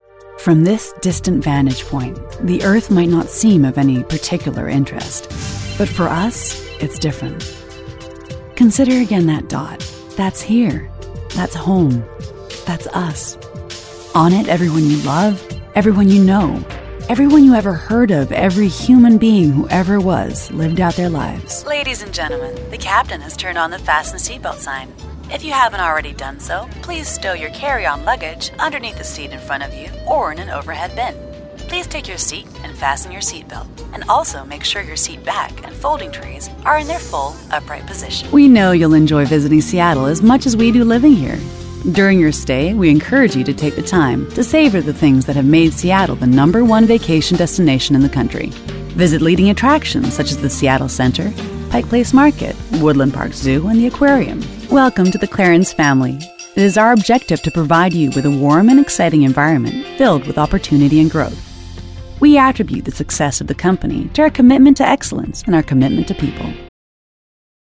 Narration Showreel
Female
American Standard
Husky (light)
Soft
Warm